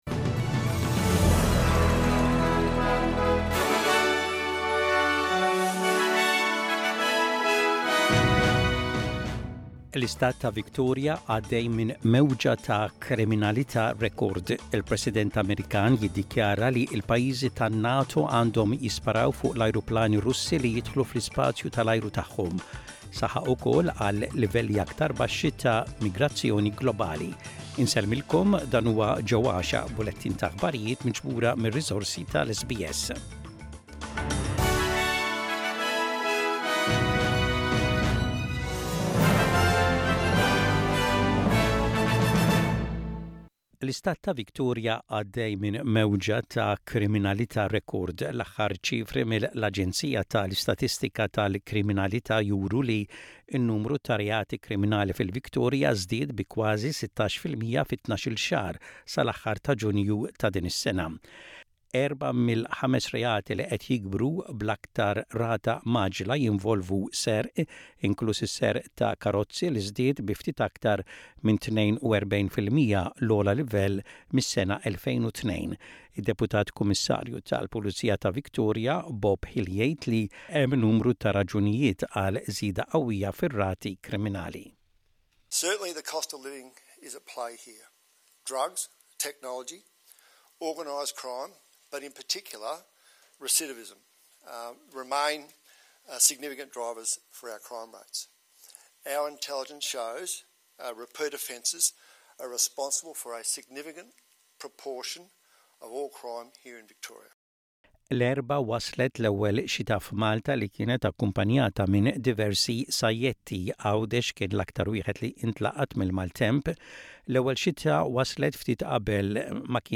SBS Maltese News: 26.09.25